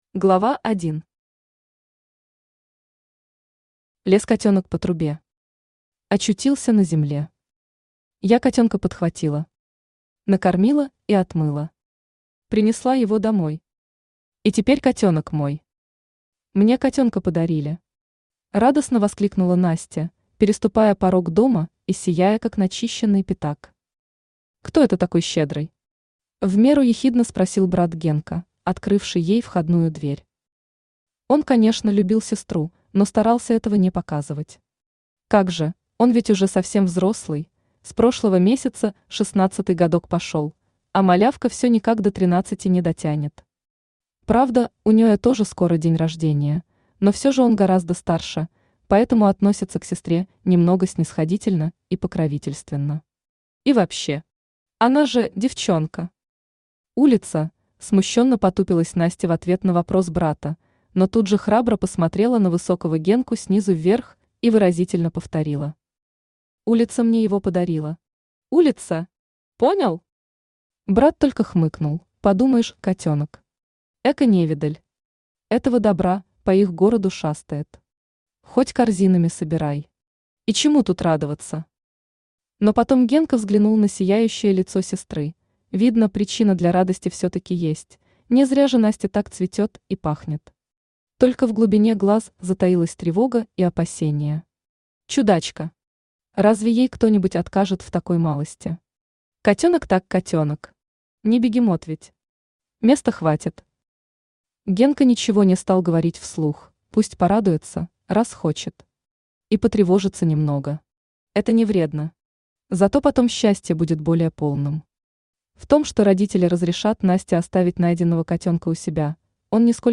Аудиокнига Кот Василий и параллельный мир | Библиотека аудиокниг
Aудиокнига Кот Василий и параллельный мир Автор Светлана Николаевна Куксина Читает аудиокнигу Авточтец ЛитРес.